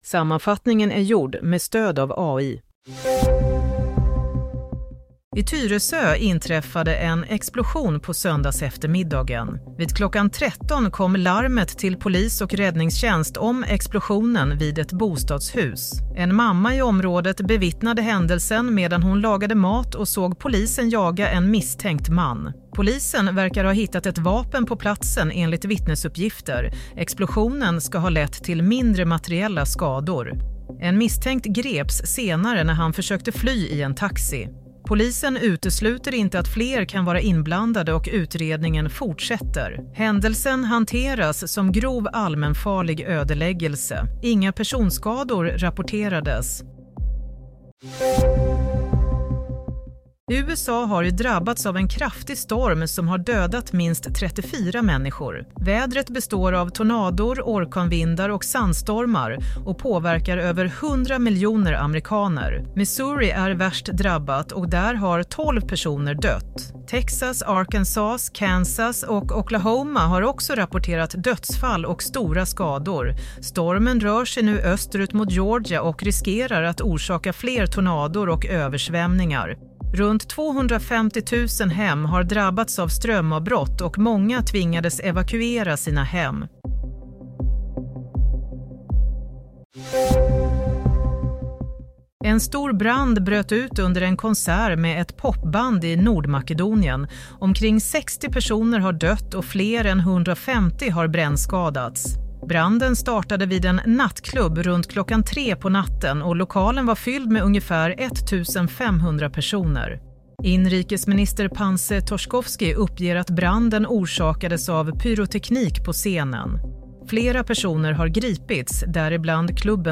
Nyhetssammanfattning - 16 mars 16:00
Sammanfattningen av följande nyheter är gjord med stöd av AI.